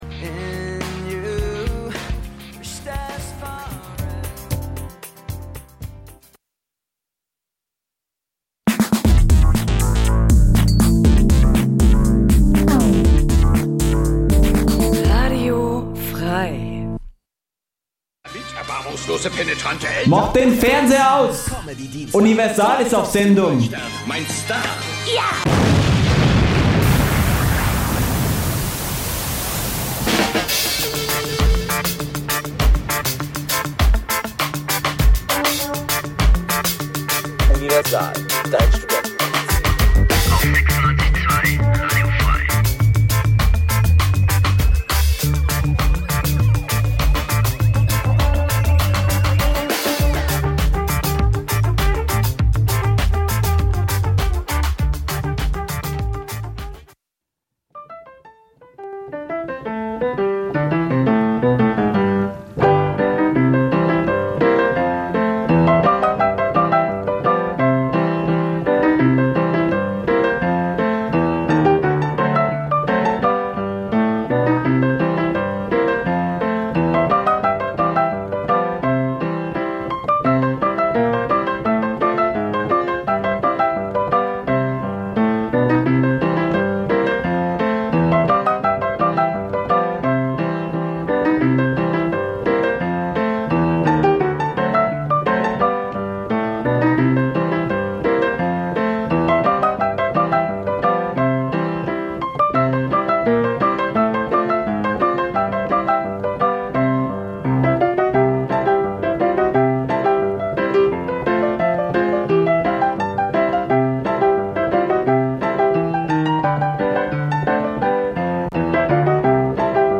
Die Sendungen werden gemeinsam vorbereitet - die Beitr�ge werden live im Studio pr�sentiert.
Studentisches Magazin Dein Browser kann kein HTML5-Audio.